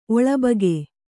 ♪ oḷabage